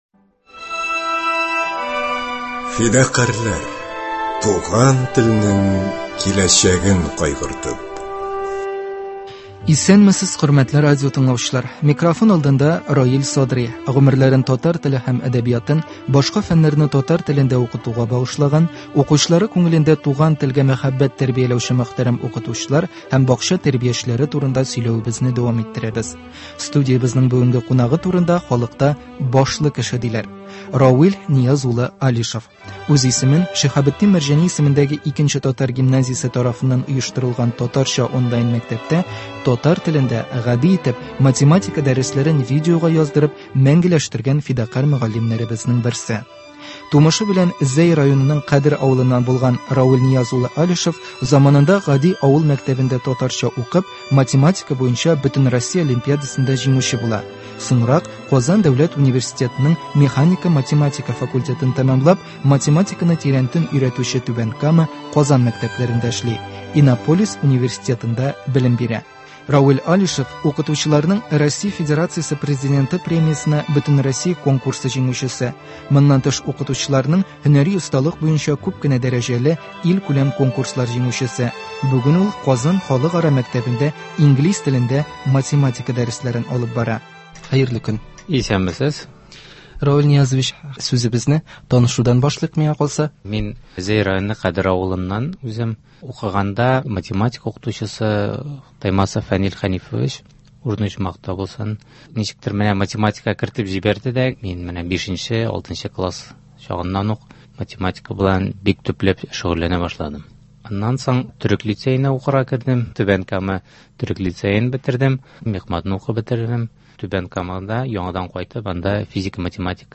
Гомерләрен татар теле һәм әдәбиятын, башка фәннәрне татар телендә укытуга багышлаган, укучылары күңелендә туган телгә мәхәббәт тәрбияләүче мөхтәрәм укытучылар һәм бакча тәрбиячеләре турында сөйләвебезне дәвам иттерәбез. Студиябезнең бүгенге кунагы турында халыкта “башлы кеше” диләр.